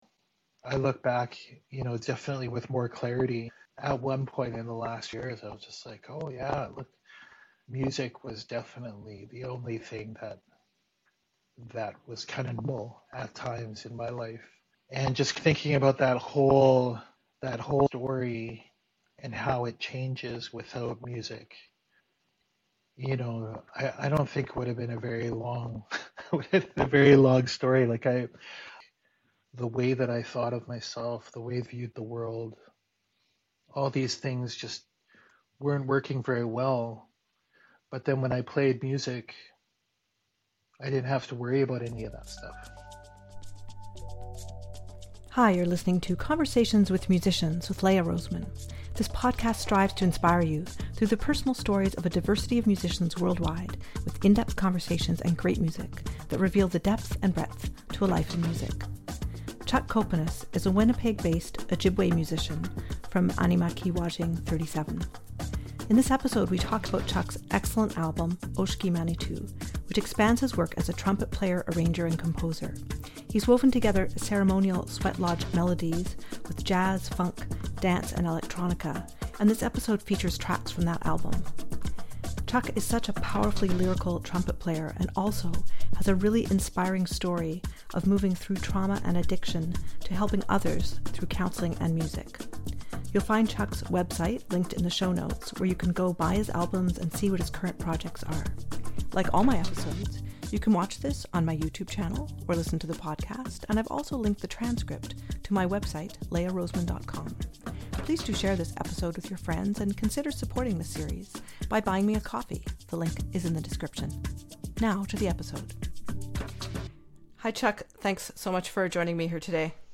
He’s woven together ceremonial sweat-lodge melodies with jazz, funk, dance and electronica and this episode features tracks from that album.